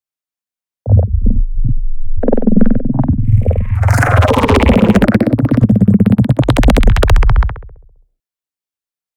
Snoring Creature